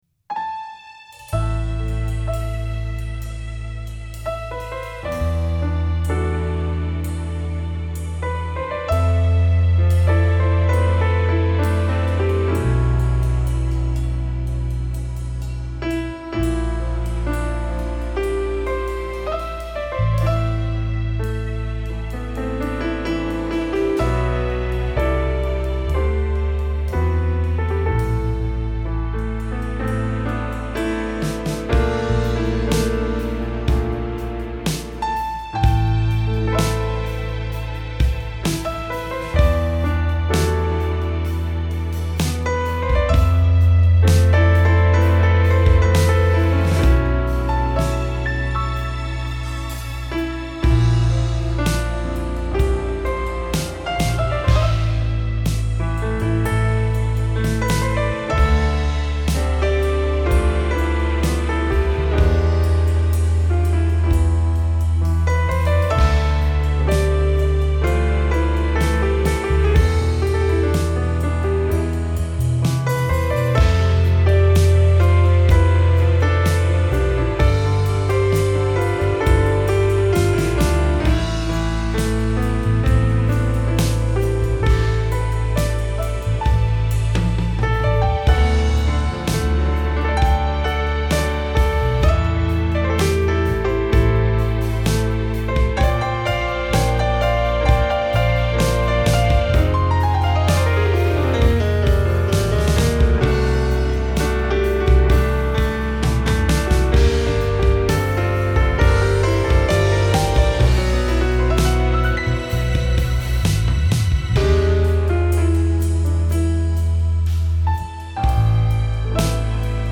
Keyboards
Bass
Drums
This is an improvised cover track.